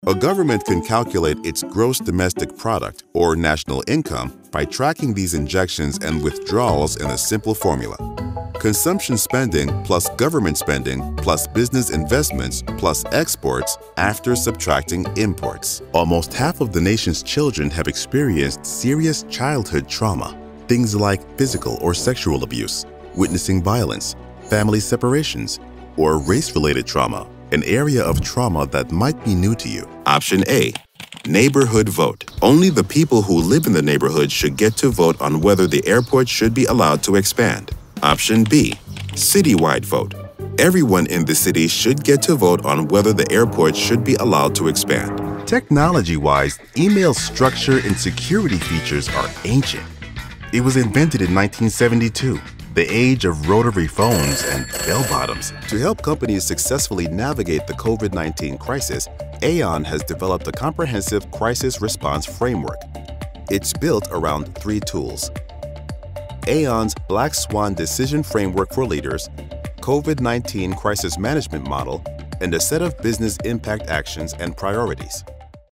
Male
Approachable, Assured, Authoritative, Confident, Conversational, Cool, Corporate, Deep, Engaging, Friendly, Gravitas, Natural, Reassuring, Smooth, Soft, Streetwise, Upbeat, Warm
American Southern, African American Vernacular English, Jamaican patois, UK (South London), New Yorker
Microphone: TLM103/MKH416